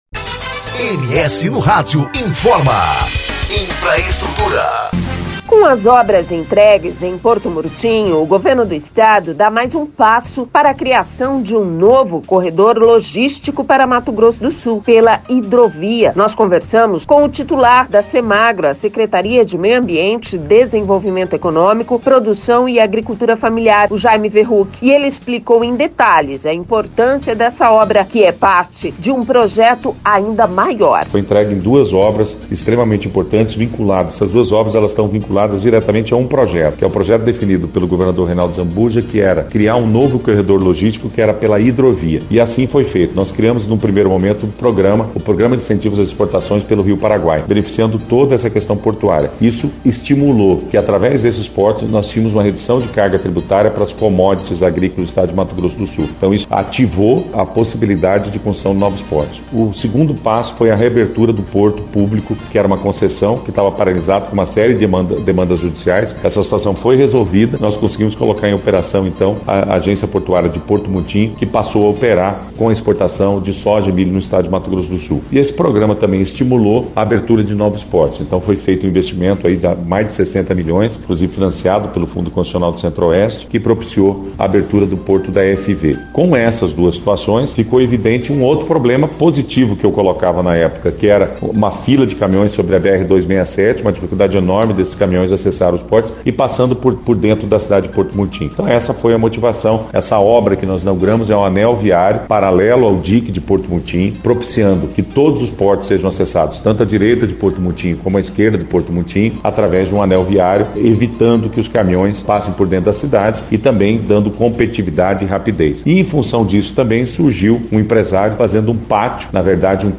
ENTREVISTA: Jaime Verruck, da Semagro, explica importância das obras entregues em Porto Murtinho, para o País
Com as obras entregues em Porto Murtinho, o Governo do Estado, dá mais um passo para a criação de um novo corredor logístico para Mato Grosso do Sul - pela hidrovia. Nós conversamos com o titular da Semagro (Secretaria de Meio Ambiente, Desenvolvimento Econômico, Produção e Agricultura Familiar), Jaime Verruck, e ele explicou em detalhes a importância desse obra, que é parte de um projeto ainda maior.